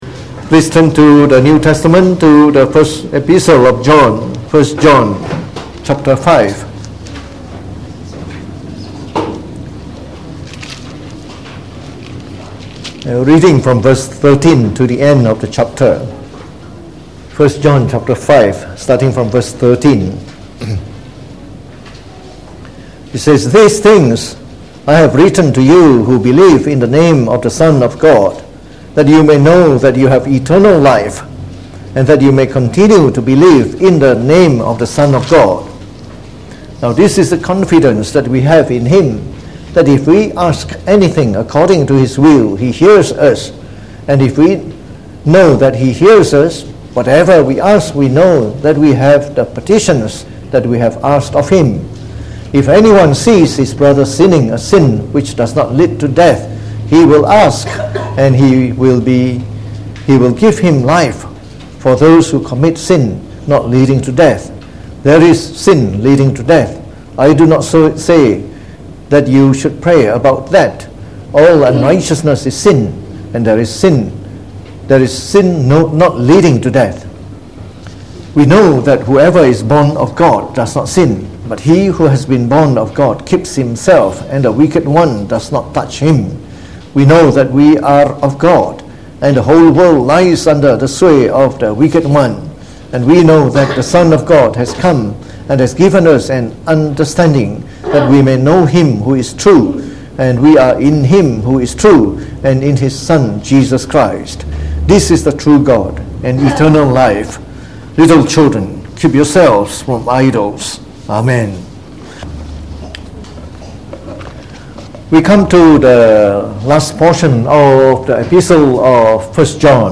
delivered in the Evening Service